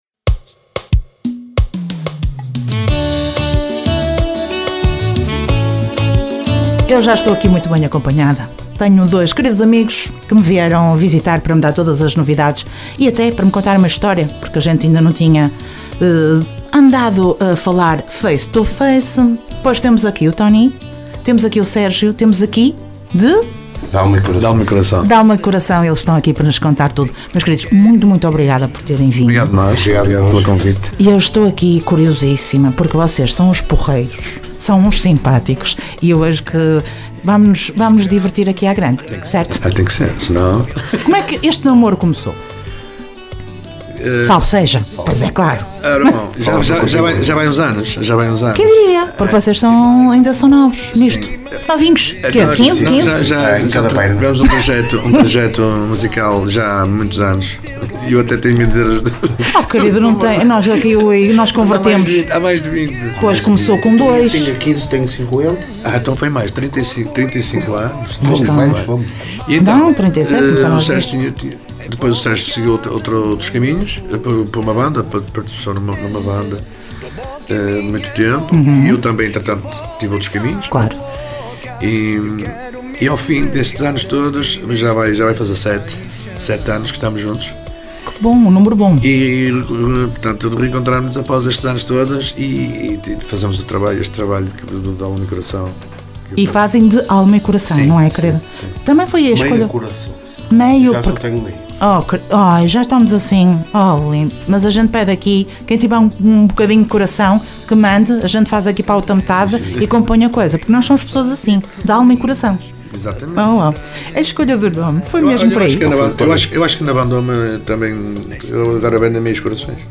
Entrevista D`alma & Coração dia 20 de Maio 2025.
ENTREVISTA-DALMA-E-CORACAO.mp3